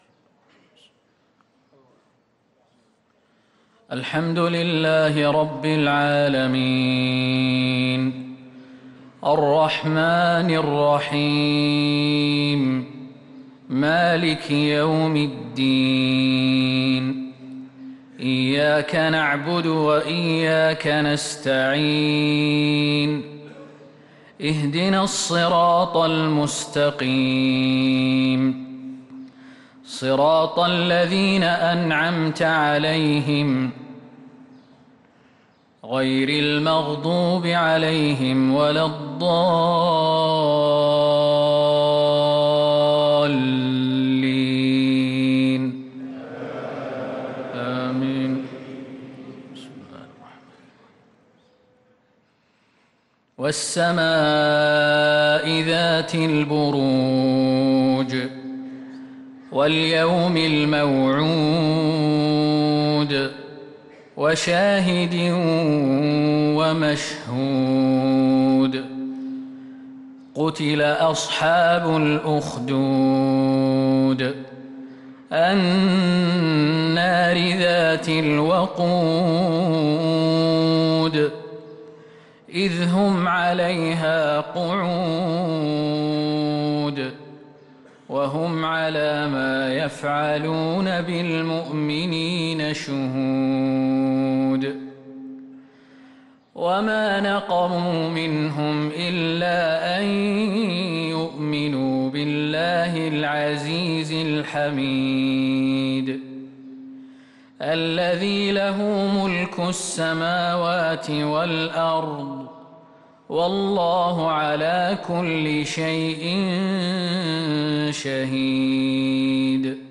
صلاة العشاء
تِلَاوَات الْحَرَمَيْن .